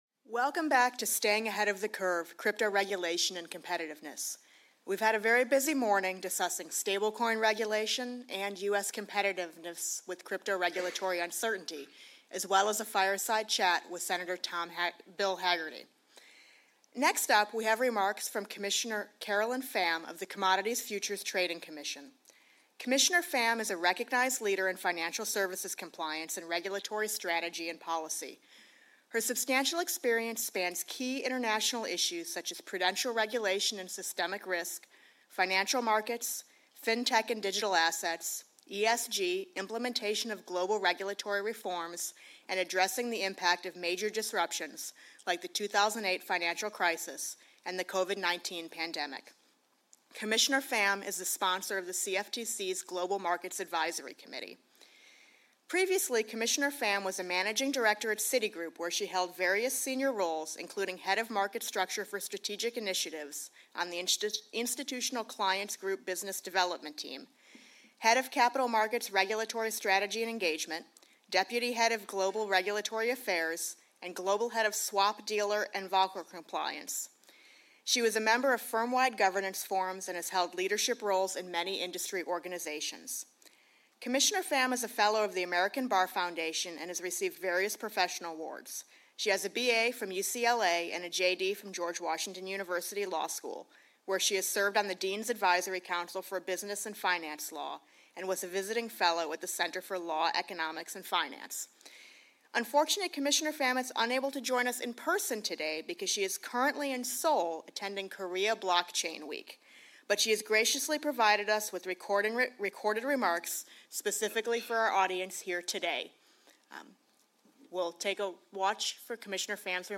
Staying Ahead of the Curve: Crypto Regulation and Competitiveness: Remarks by Caroline Pham
Join us at Cato’s Center for Monetary and Financial Alternatives Annual Conference for an outstanding program featuring leading policymakers and experts discussing crypto regulation.